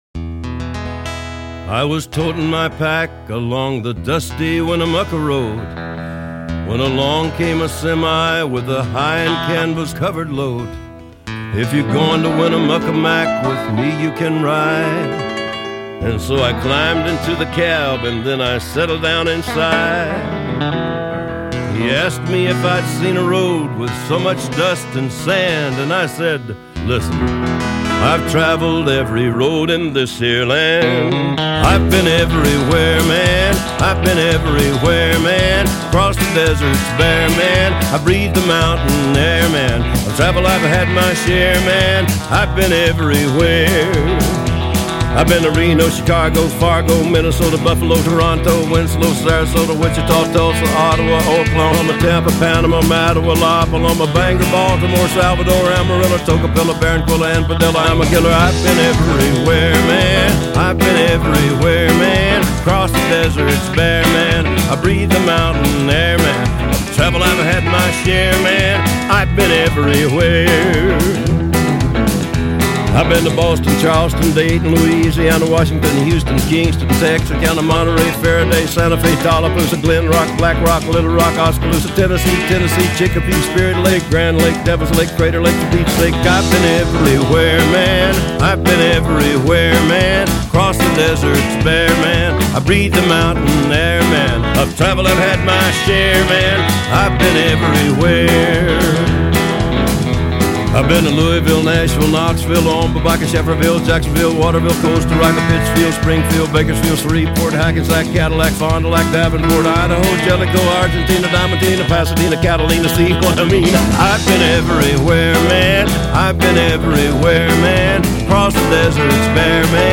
country کانتری